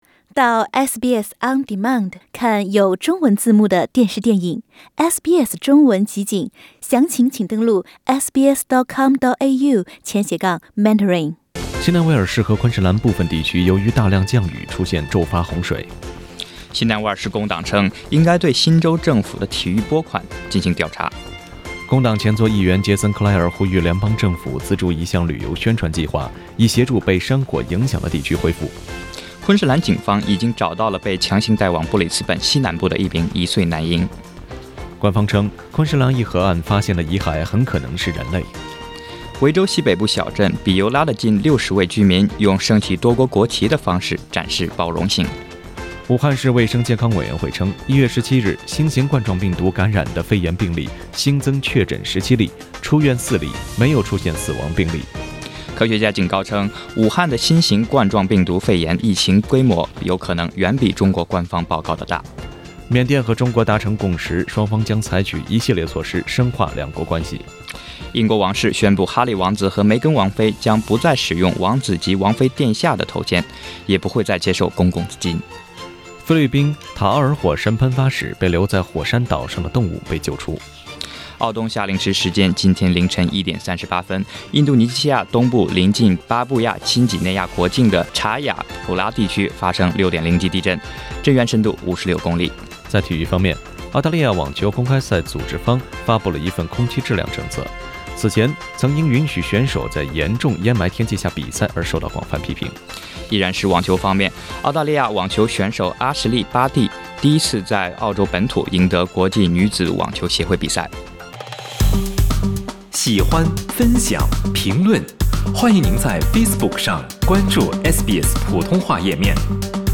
SBS早新闻（01月19日）